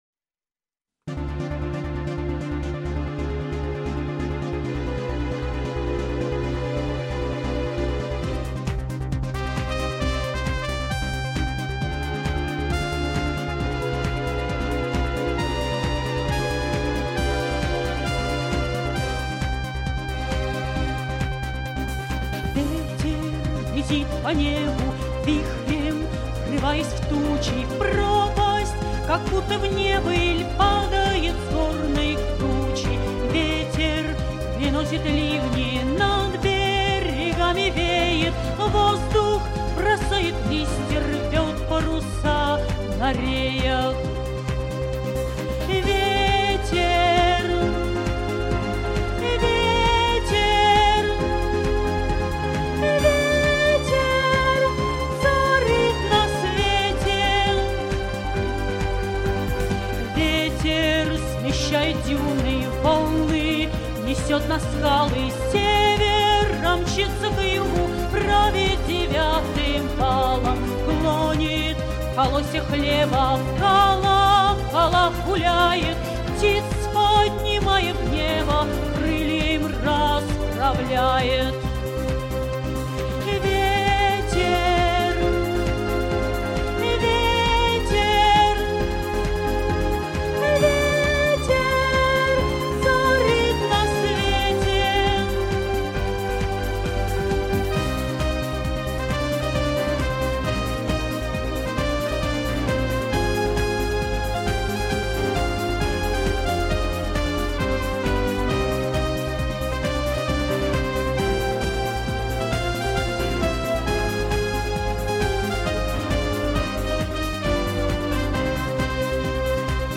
Детская песня